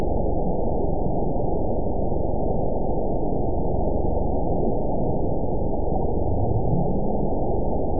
event 922327 date 12/30/24 time 00:48:48 GMT (11 months ago) score 8.70 location TSS-AB02 detected by nrw target species NRW annotations +NRW Spectrogram: Frequency (kHz) vs. Time (s) audio not available .wav